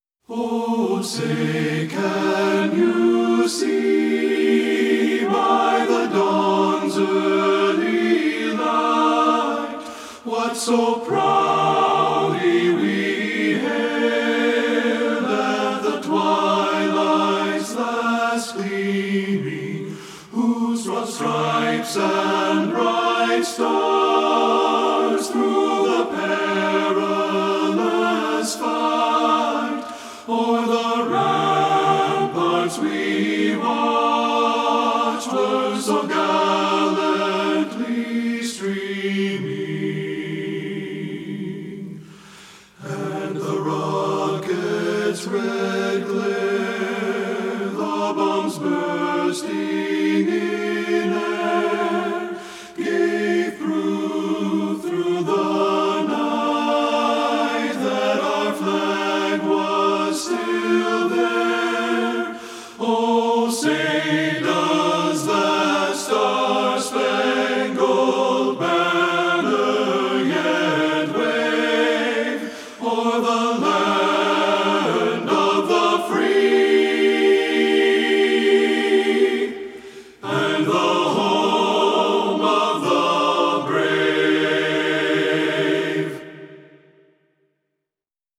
• Tenor 1
• Tenor 2 (opt.)
• Bass
Studio Recording
Ensemble: Tenor-Bass Chorus
Key: B major
Tempo: Moderato (q = 100)
Accompanied: Accompanied Chorus